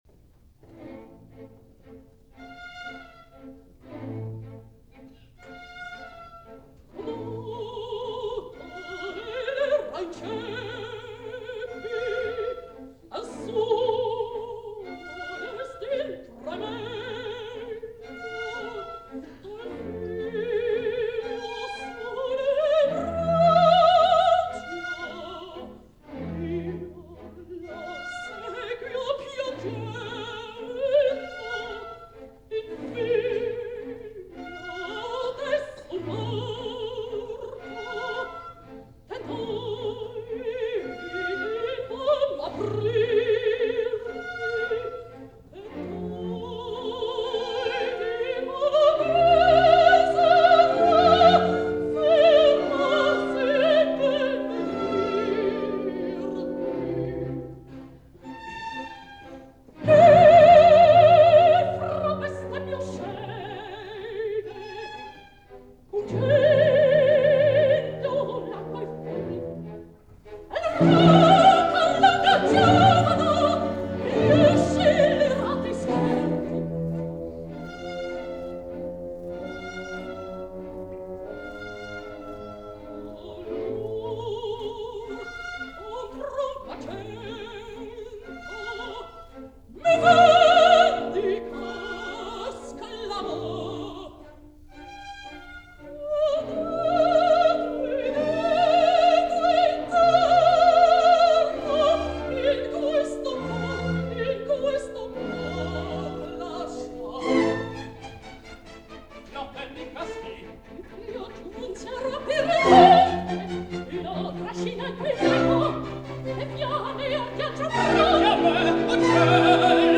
Een geluidsopname van deze productie verscheen op CD (Osteria OS-1001).